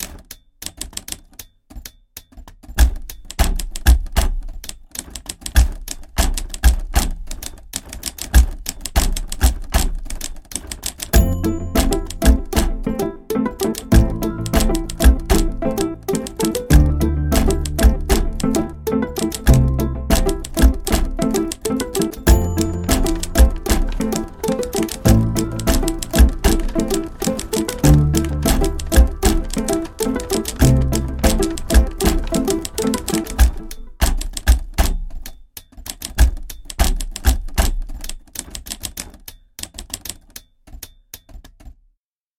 打字机是一种Kontakt采样仪器，采样于皇家老式打字机。
在Calmaestudis录音室使用4个麦克风位置精心采样：近距离立体声，立体声带，单声道和立体声房间。
记录有 3 个速度层和 10 个循环，不仅是按键，还有纸张噪音和打字机的敲击、滚动等等。